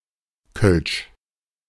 • The [l] sound is "darker" than in Standard German, and is replaced by [ɫ(ː)] throughout ("Kölsch": (Colognian) [kœ̂ɫːɕ]; (Standard German)
[kʰœlʃ])
De-Kölsch.ogg.mp3